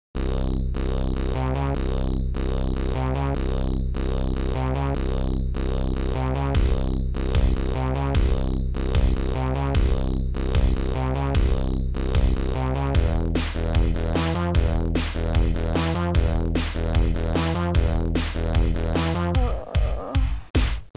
Music ( Soundtracker 15 Samples )